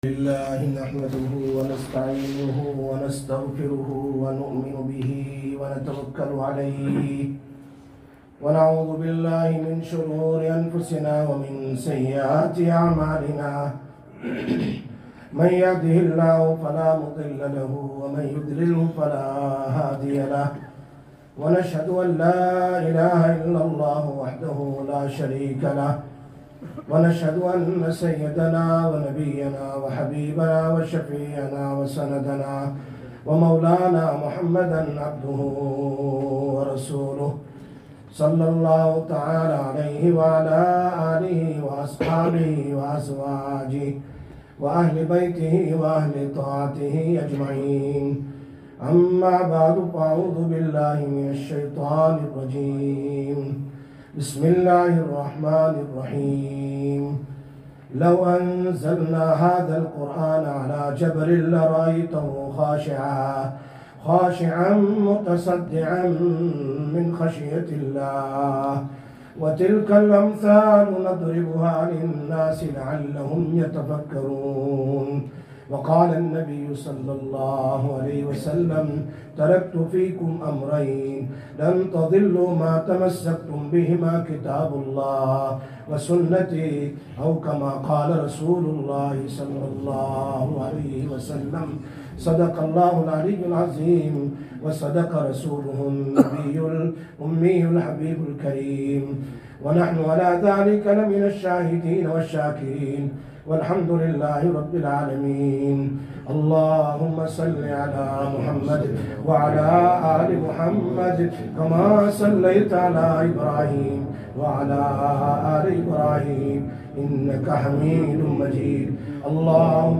14/09/2024 Khatam e Quran, Masjid Bilal